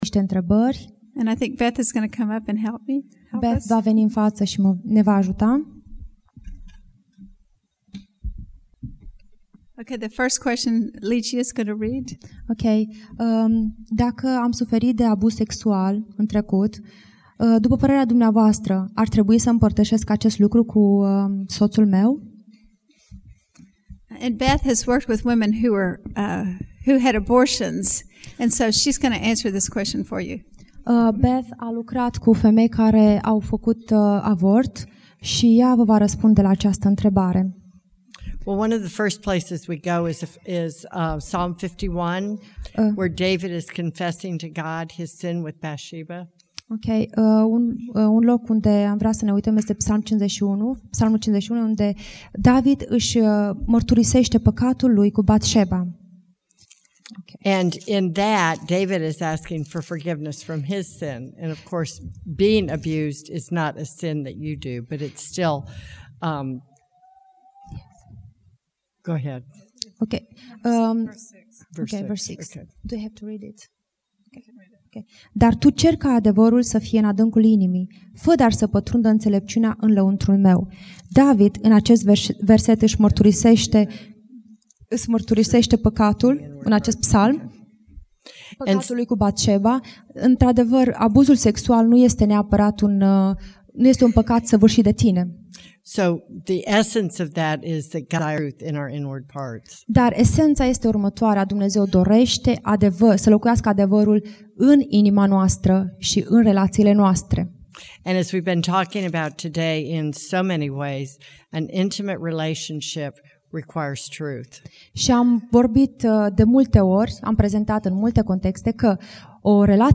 Conferinta Perle de aducere aminte: Sesiune intrebari